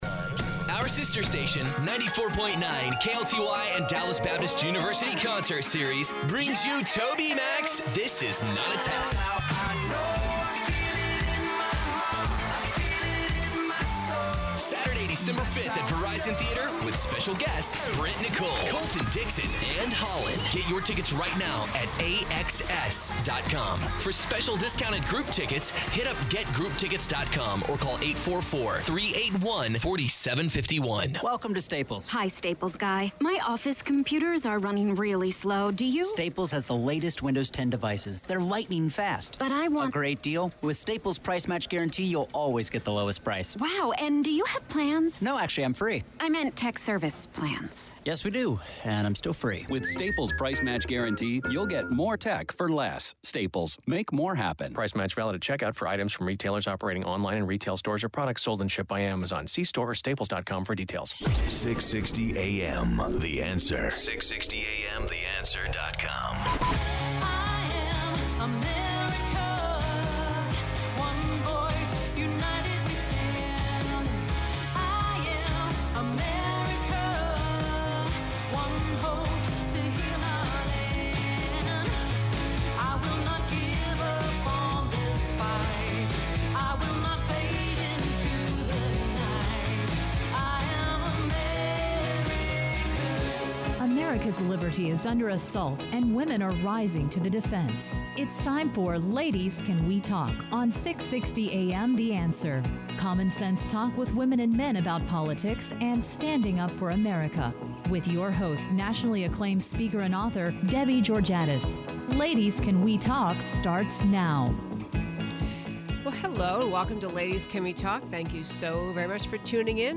Listen here to the first hour of our October 25th show.